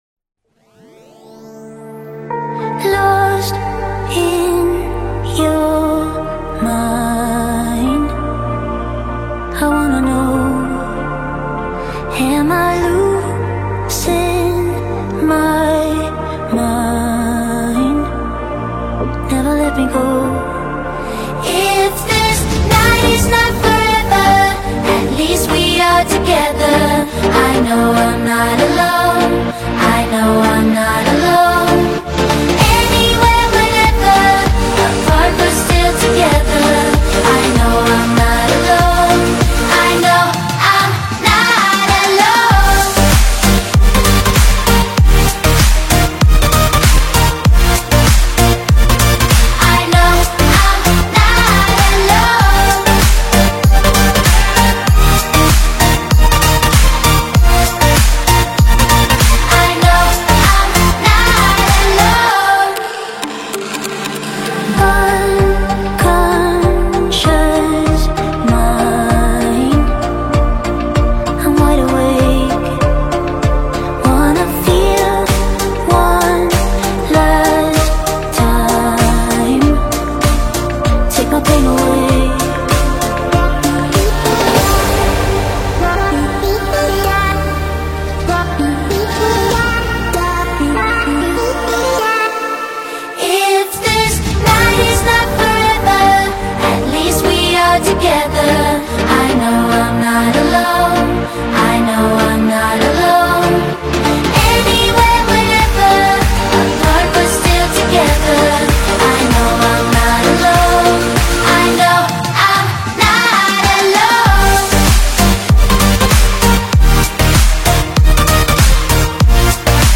melodious vocals
dance/electronic